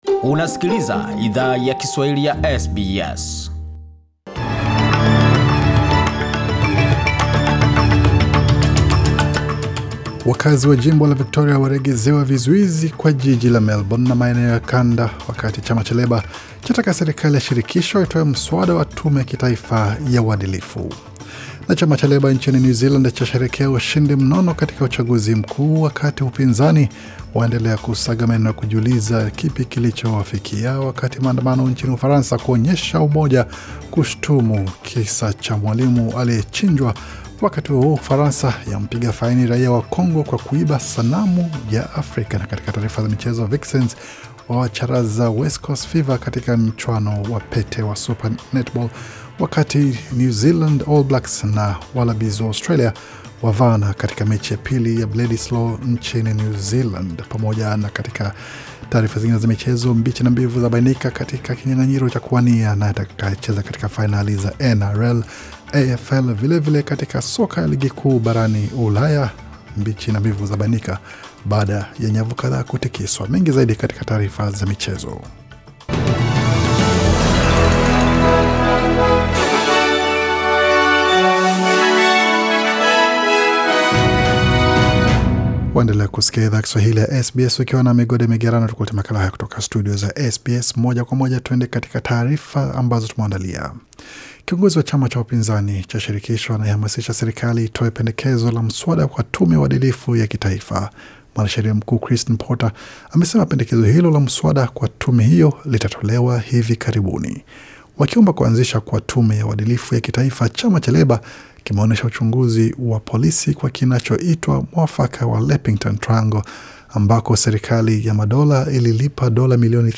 Taarifa ya habari 18 Oktoba 2020